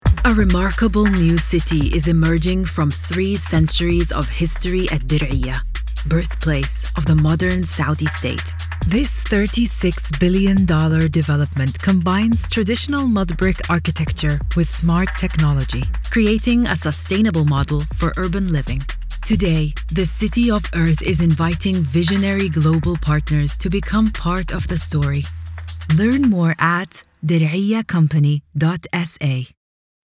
Bonjour ! Je suis un comédien de doublage primé, parlant couramment l'anglais (accent britannique et accent arabe) et l'arabe...
Autoritaire
Sur de soi